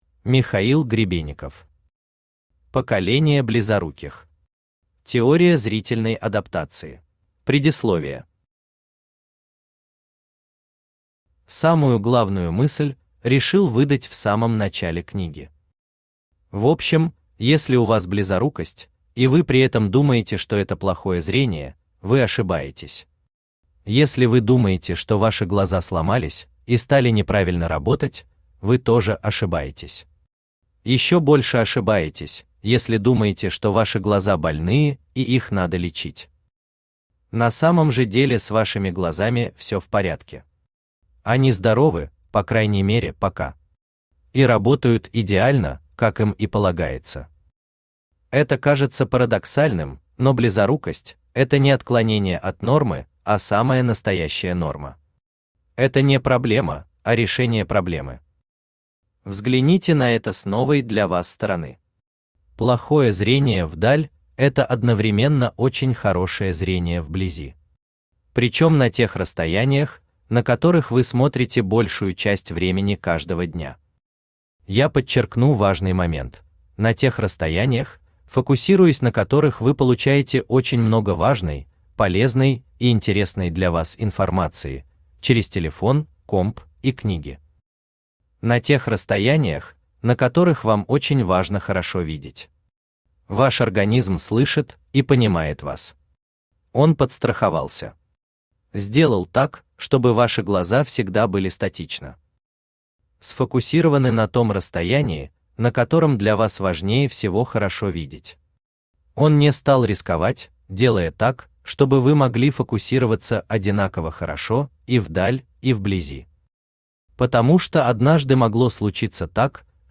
Аудиокнига Поколение близоруких. Теория зрительной адаптации | Библиотека аудиокниг
Теория зрительной адаптации Автор Михаил Валерьевич Гребенников Читает аудиокнигу Авточтец ЛитРес.